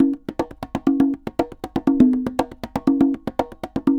Congas_Baion 120_1.wav